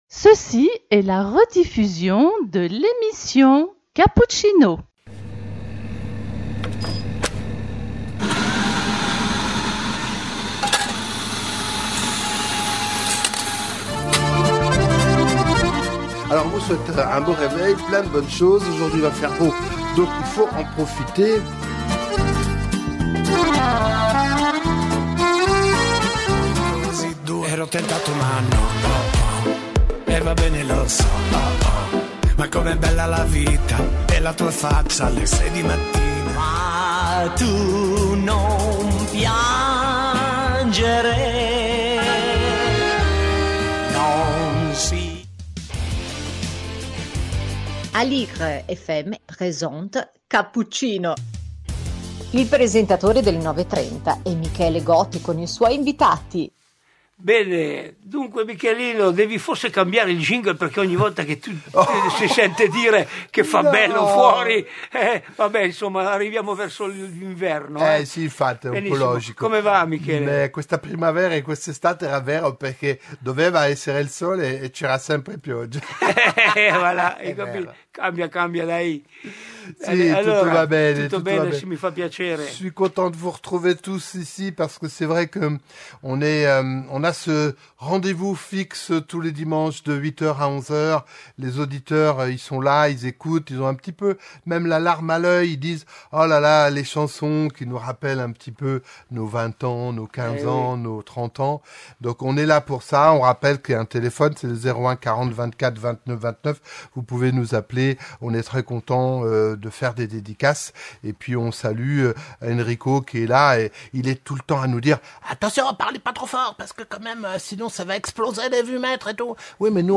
Cappuccino # 3 novembre 2024 - invités Milena Vukotic, comédienne et Alberto Toscano, politologue